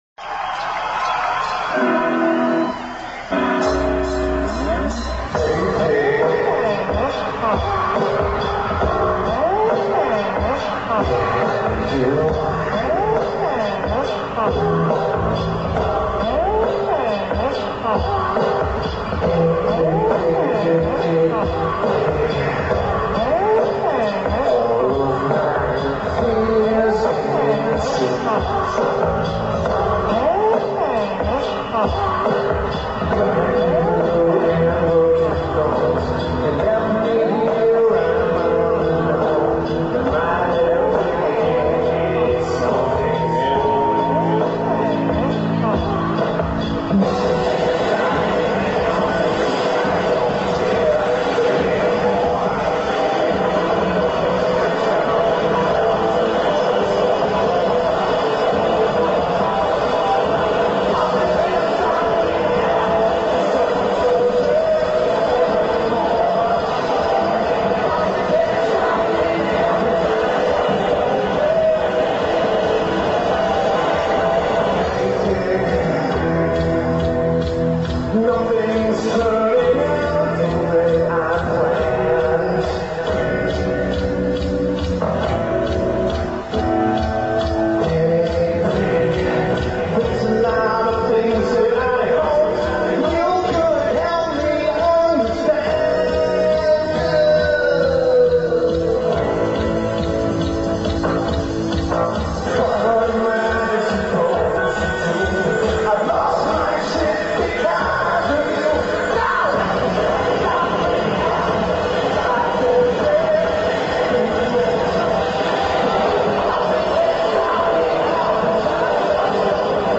Hershey Park Stadium
Below average recording.